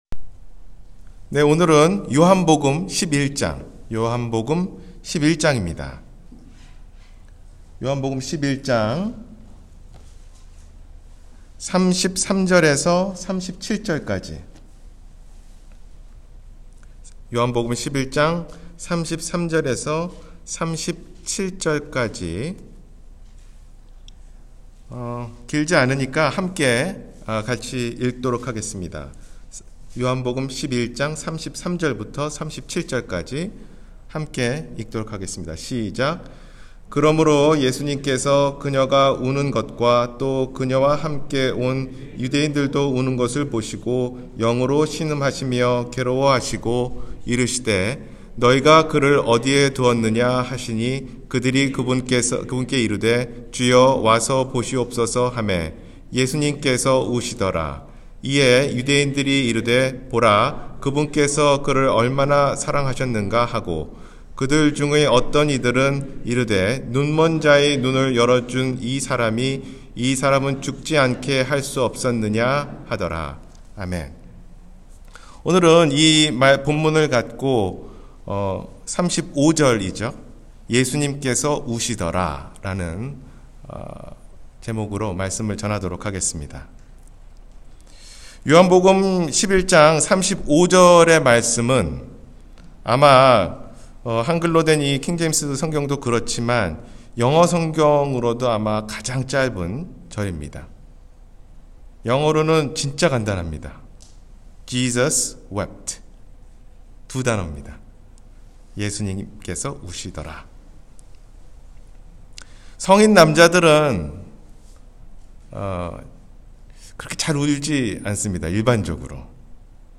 예수님께서 우시더라 – 주일설교